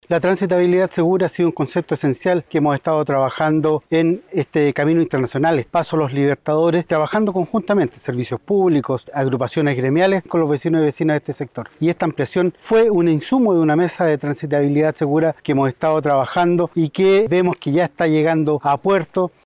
El delegado presidencial provincial de Los Andes, Cristián Aravena, detalló que el proyecto surge tras una serie de reuniones con el mundo privado y la comunidad debido a los problemas viales que generaban los camiones estacionados en el radio urbano.